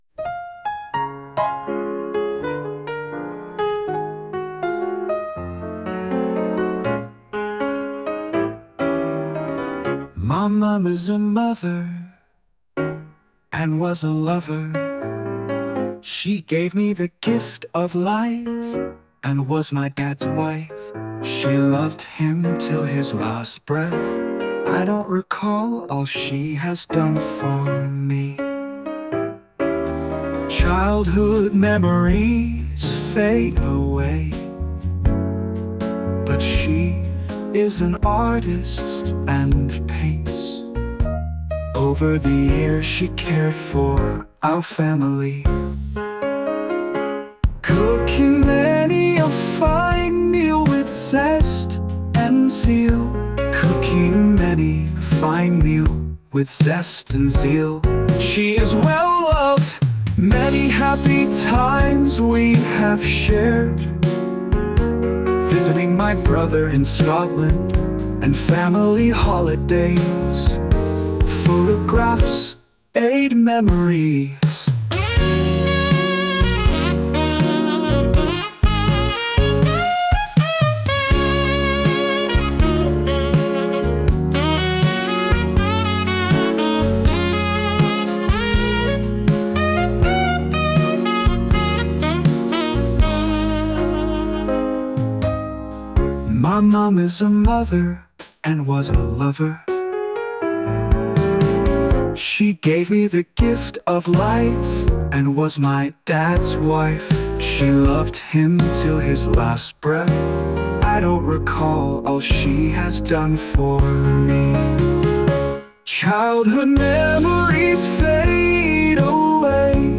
JAZZ STYLE MALE VOCALS